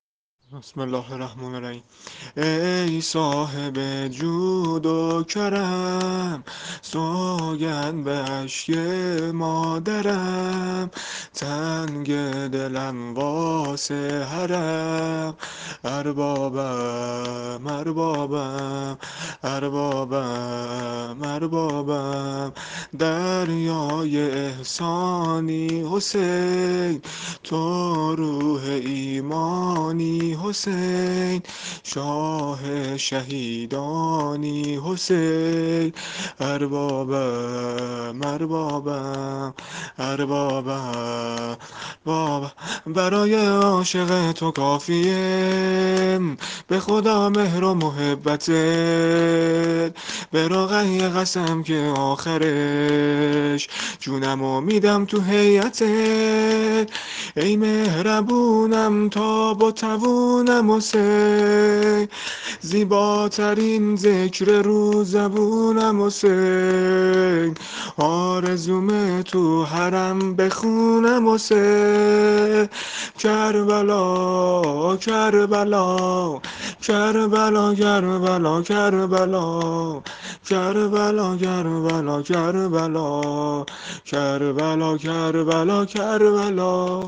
شور مناجات با ابا عبدالله علیه السلام -(ای صاحب جود و کرم)